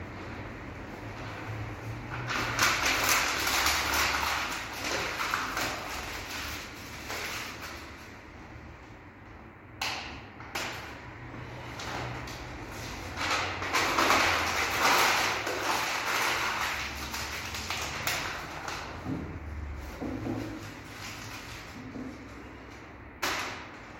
Performance, 2022.
Body, chair, envelope, letters.
caduta1.mp3